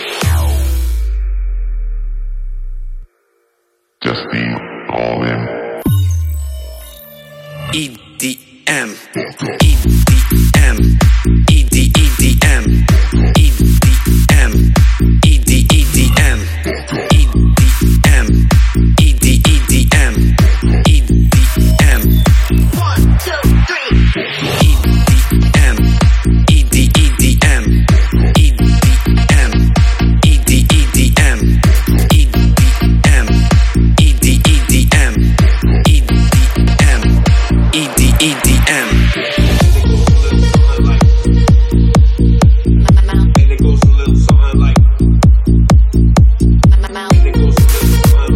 重低音好きのための私